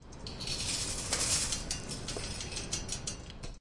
家庭噪音 " 手推车滚动
描述：正在滚动的轮子上的物品。
标签： 购物车 椅子 拨浪鼓
声道立体声